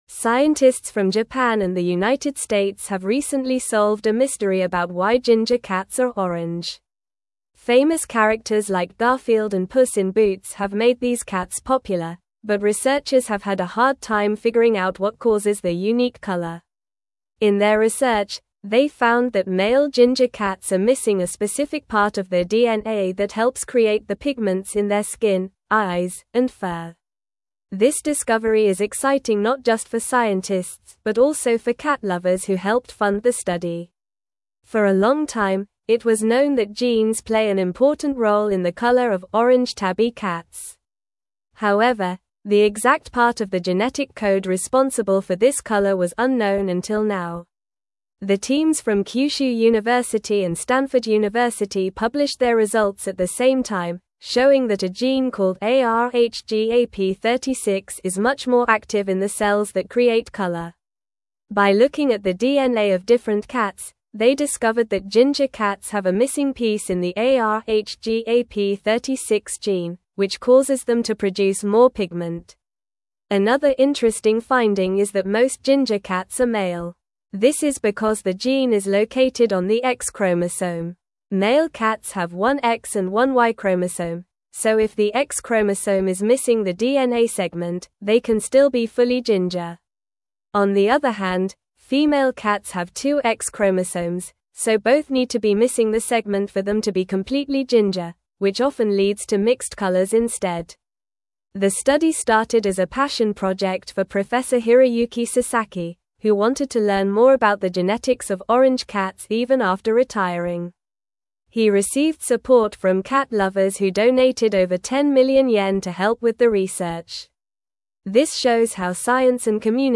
Normal
English-Newsroom-Upper-Intermediate-NORMAL-Reading-Genetic-Mystery-of-Ginger-Cats-Unveiled-by-Researchers.mp3